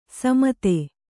♪ samate